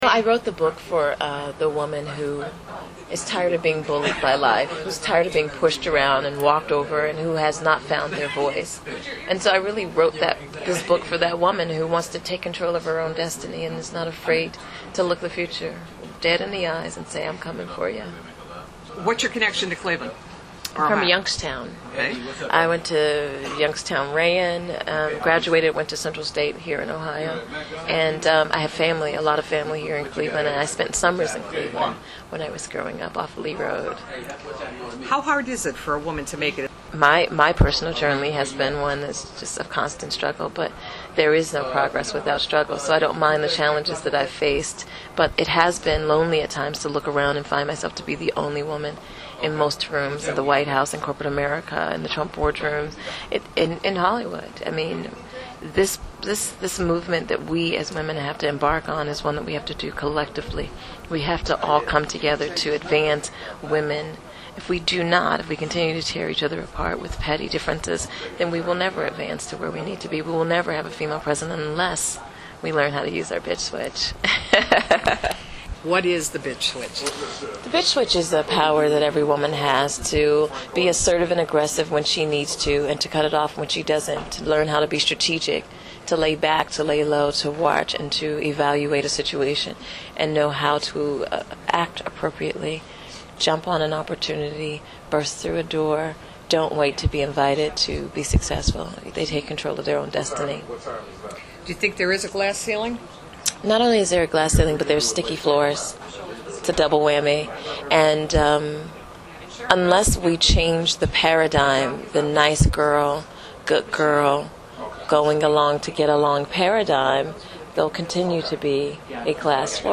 The woman everyone loves to hate made a stop at Crooked River Books at the Galleria to sign her book, The Bitch Switch: Knowing How to Turn It on and Off.